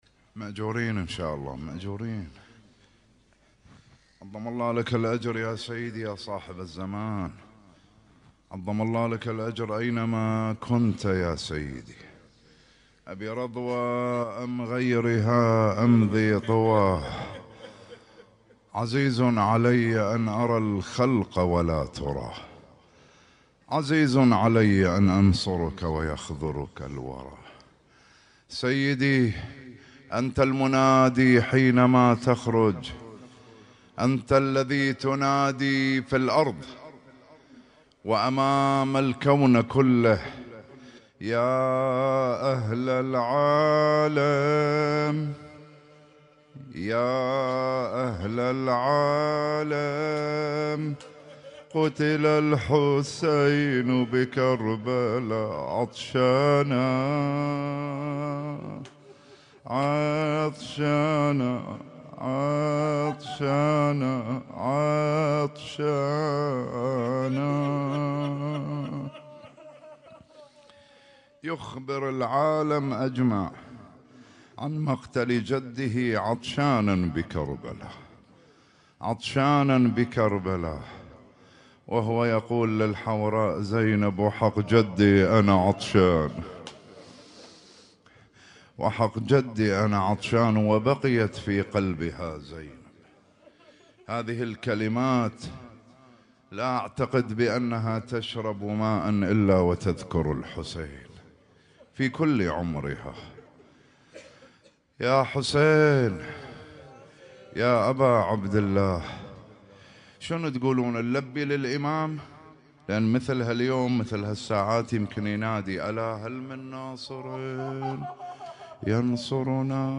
يوم عاشوراء محرم الحرام 1447هـ